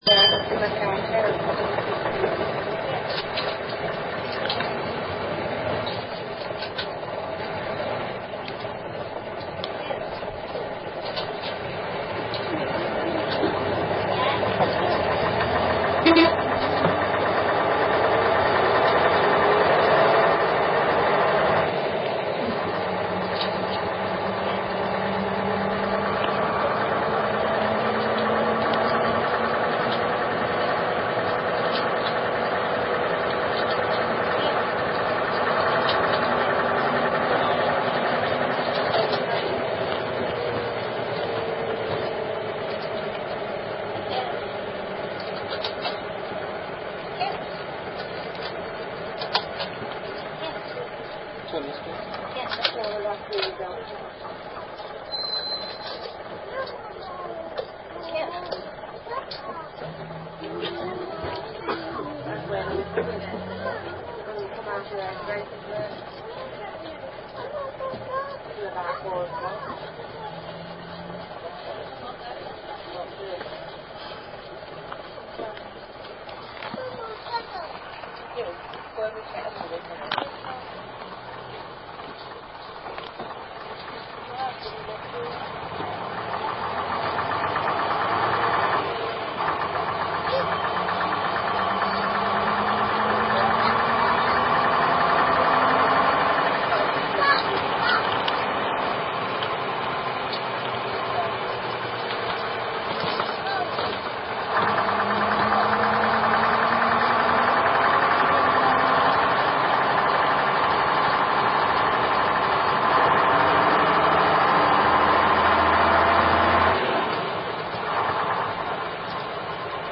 Daimler CVG6 (Derby Corporation) (so)
Derby Corporation a 1966 Daimler CVG6/Roe No. 187 (KRC 187D) on route 11, heading for Allestree from the town centre.
Derby_Daimler_CVG6.mp3